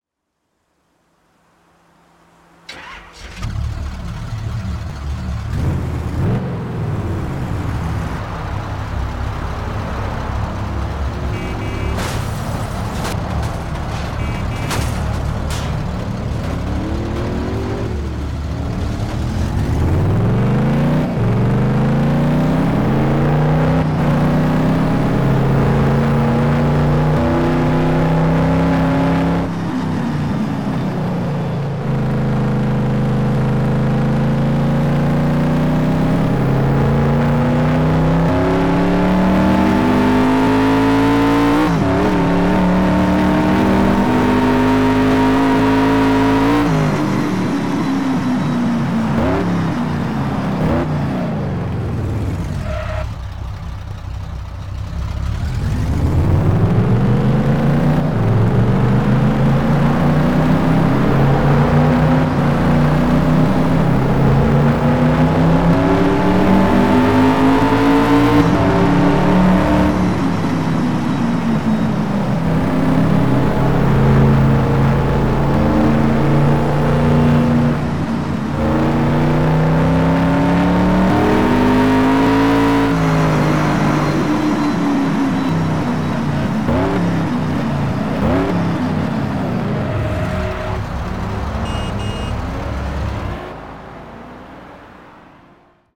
- Ford Shelby GR-1 Concept